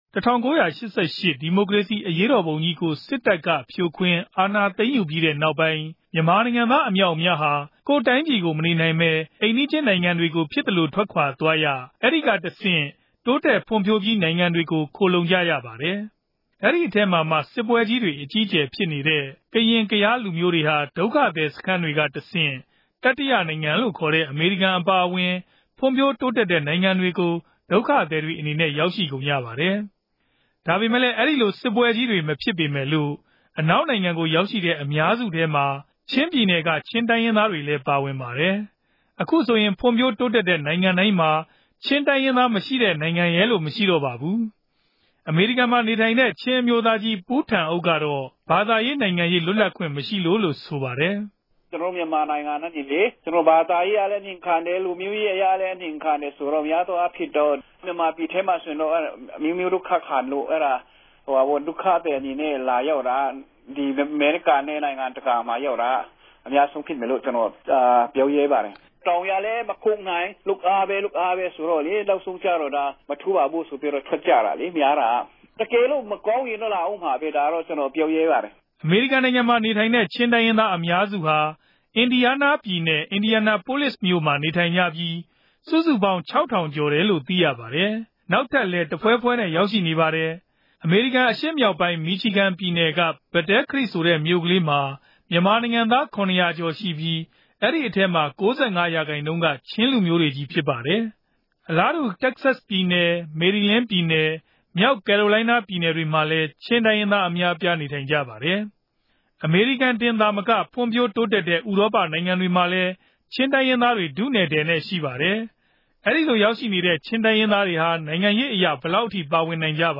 စုစည်းတင်ူပမေးူမန်းခဵက်။